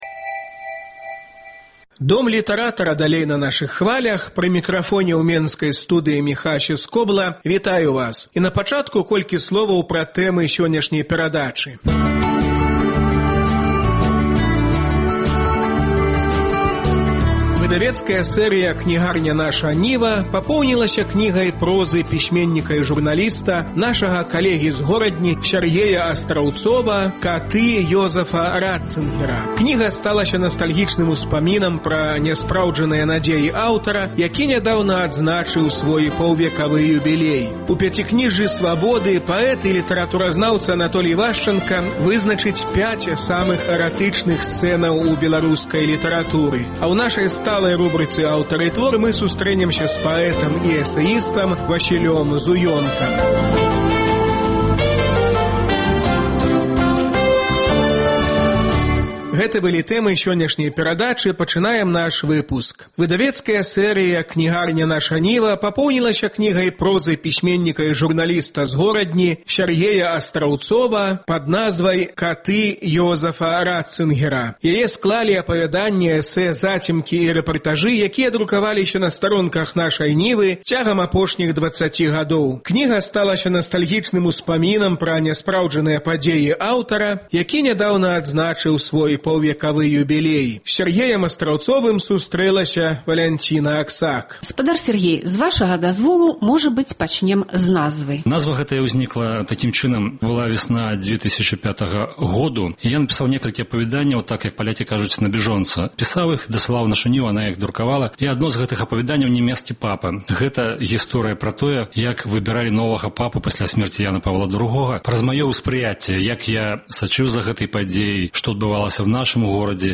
Размова зь пісьменьнікам